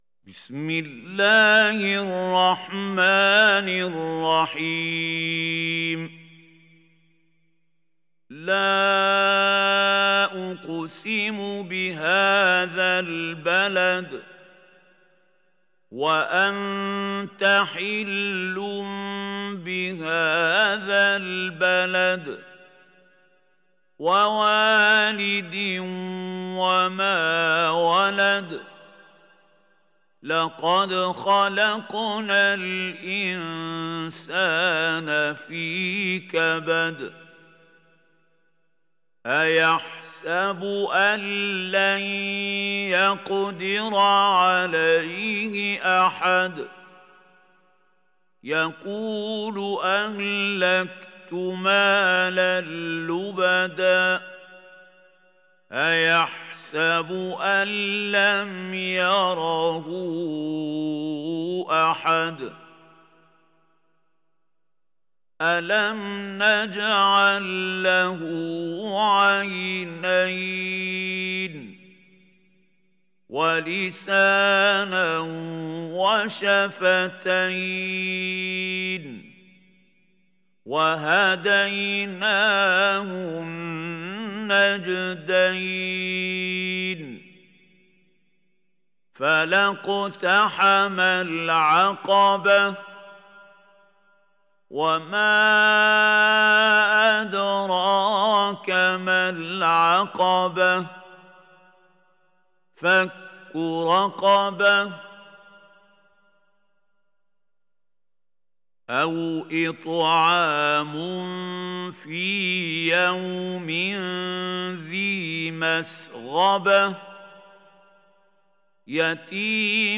دانلود سوره البلد mp3 محمود خليل الحصري روایت حفص از عاصم, قرآن را دانلود کنید و گوش کن mp3 ، لینک مستقیم کامل